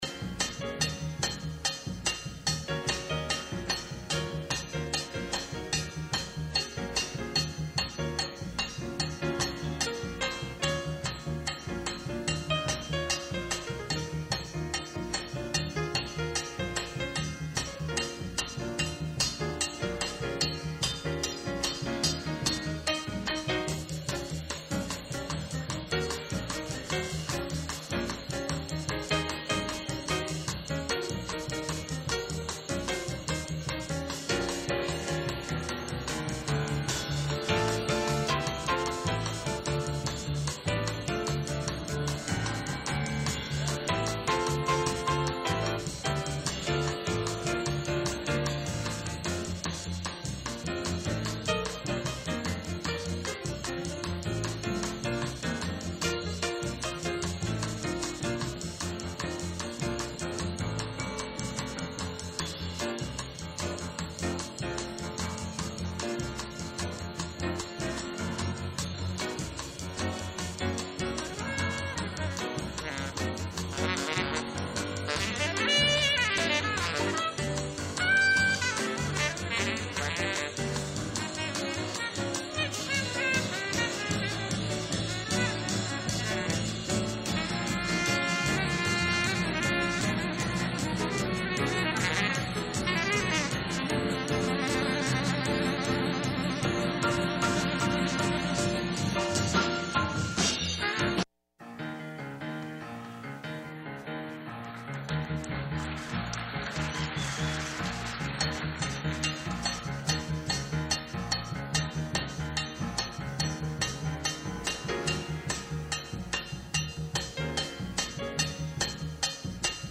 Livres 9 est une émission diffusée sur les ondes de la Radio Magik 9, 100.9 de janvier 2014 à juillet 2019.